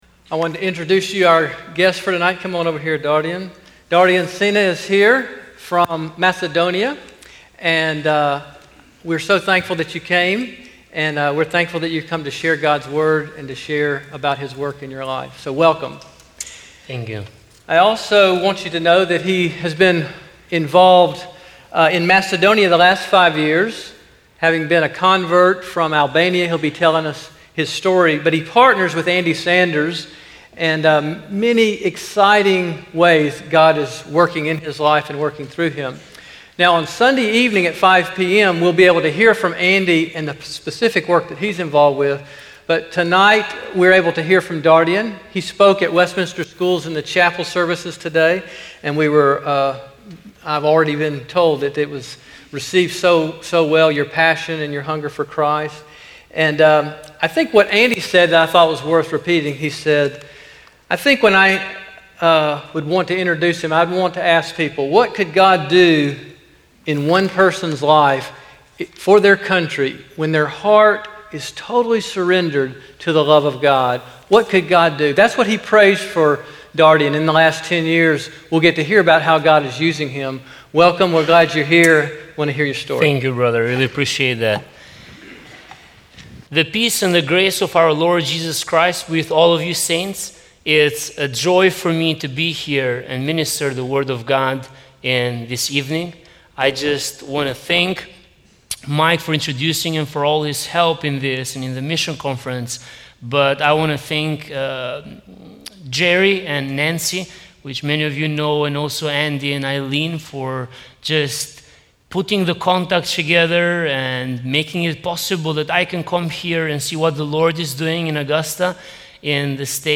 Sermons - First Presbyterian Church of Augusta
2016 Bible & Missionary Conference Session 1